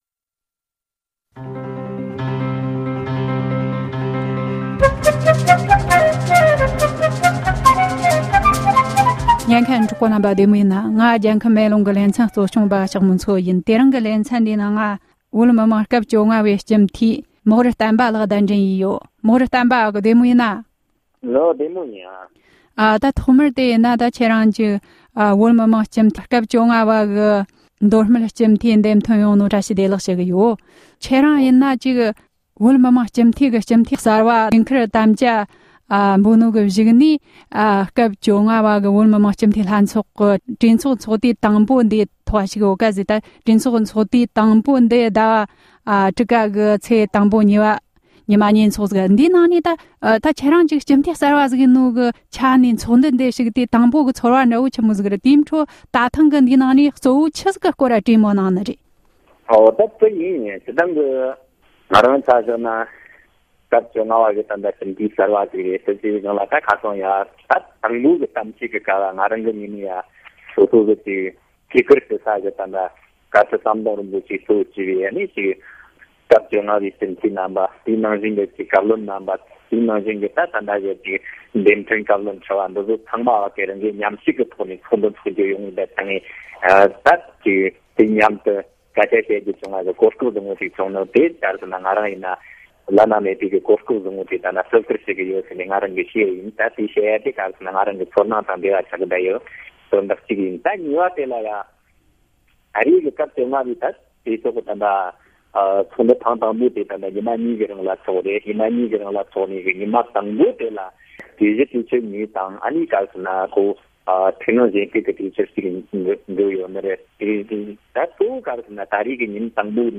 བཅར་འདྲི་བྱས་ཡོད༎